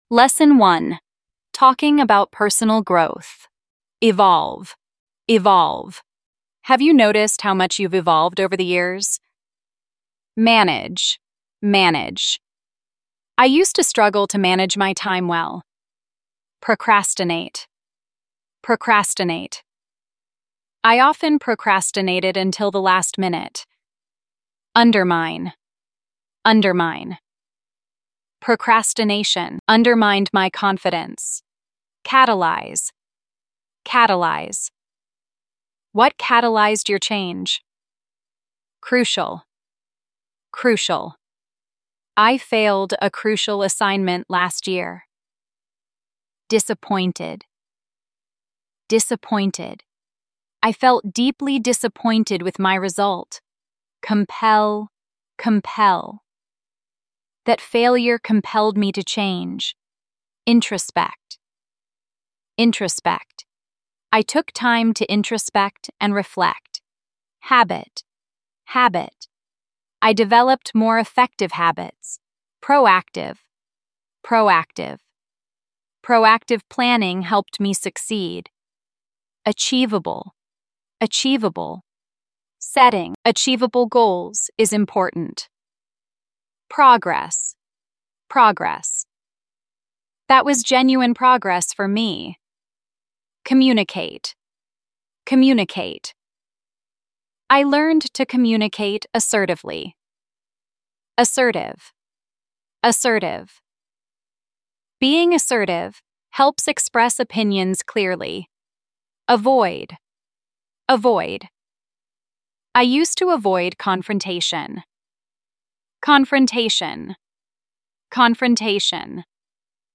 Nghe thử sách: Giọng tự nhiên